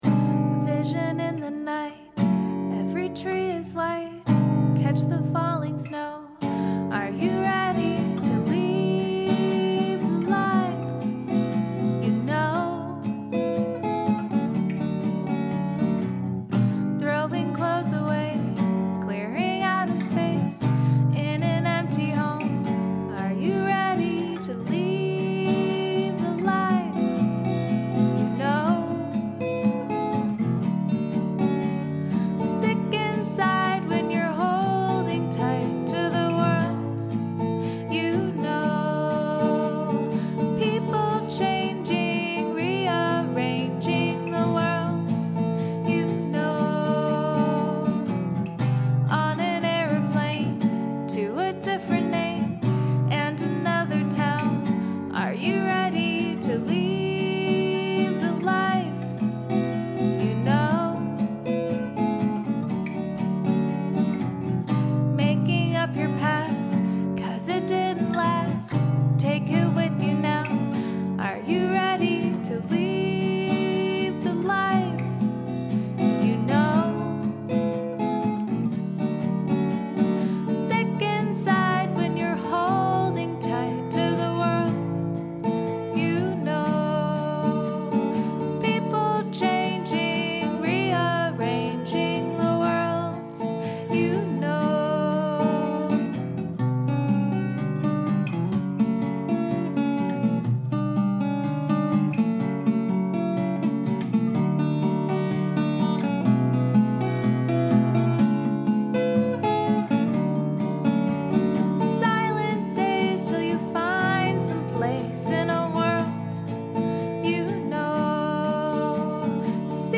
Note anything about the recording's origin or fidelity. in his home studio